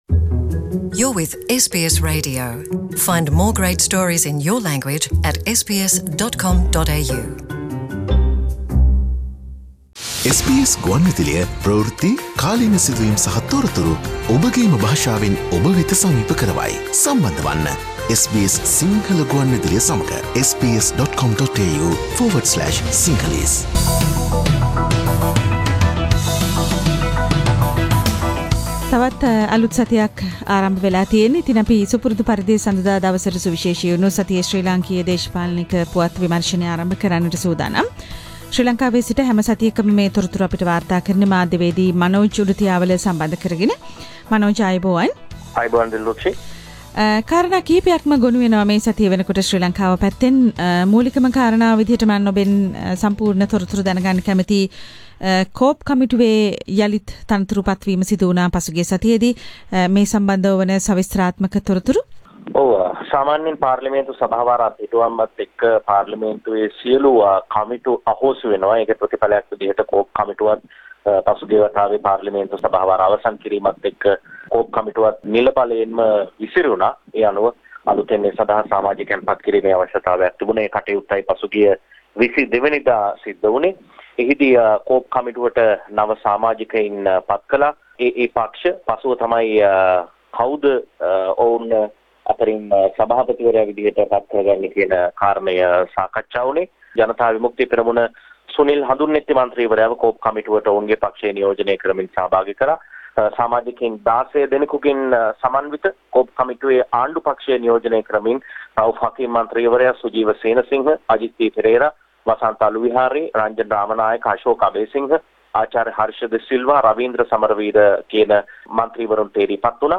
සතියේ දේශපාලන පුවත් සමාලෝචනය